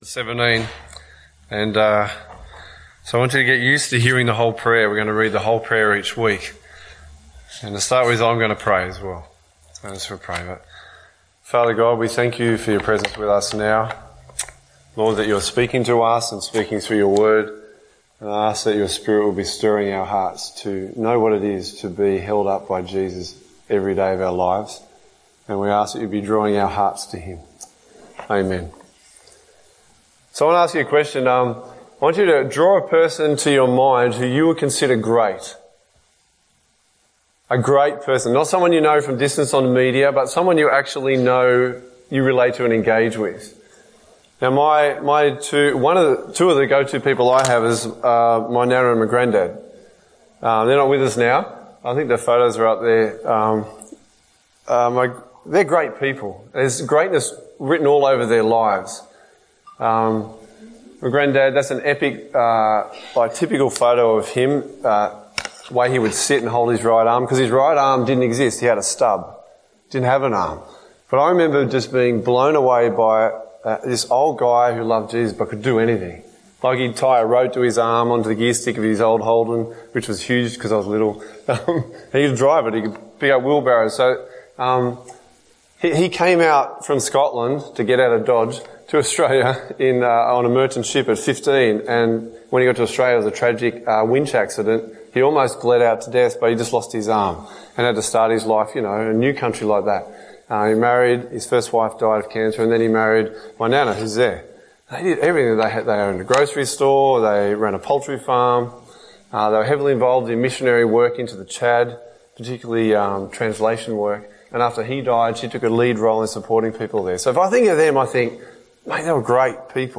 A message from the series "My Life Verse."